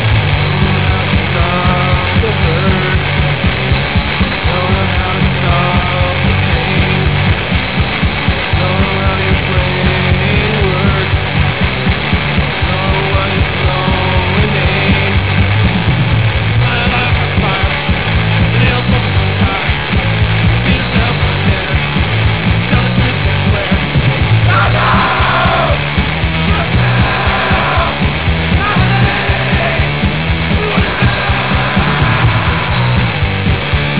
We did it at Sound Dimensions in Timmins.
All the samples are .wav, 8KHz, 8-bit